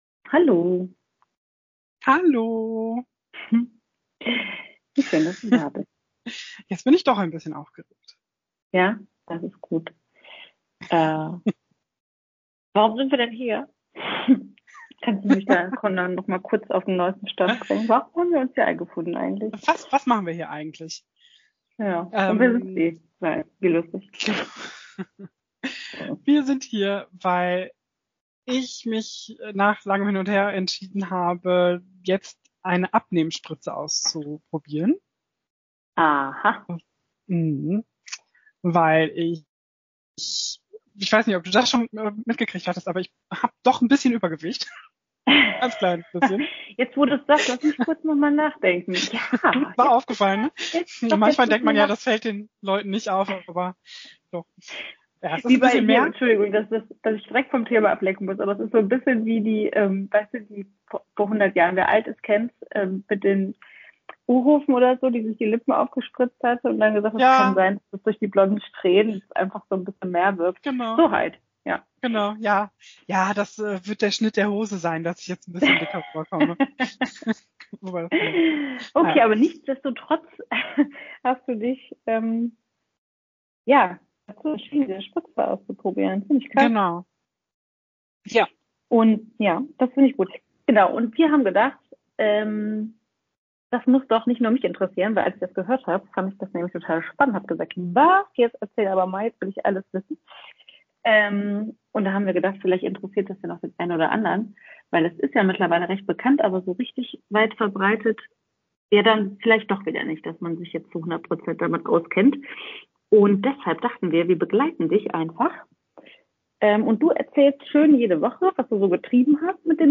Ein ehrliches Gespräch über große Ziele, kleine Fortschritte und ein bisschen Nervosität vor der ersten Dosis.